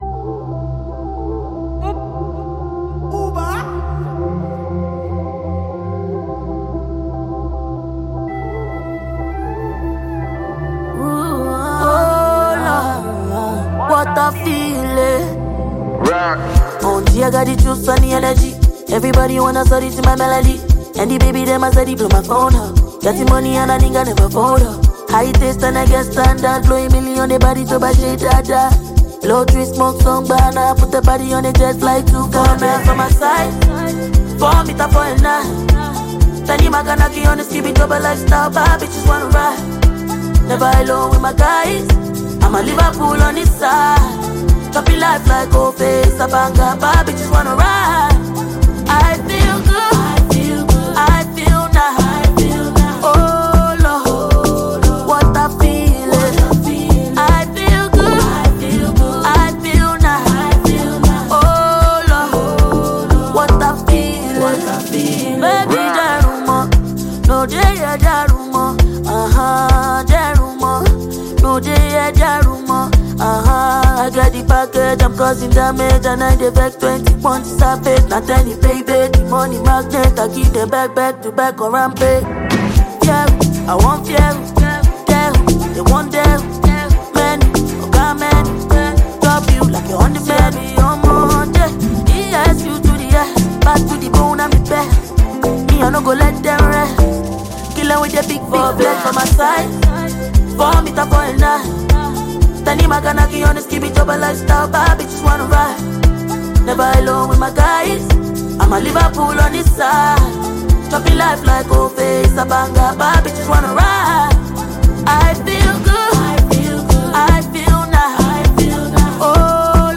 catchy unique sound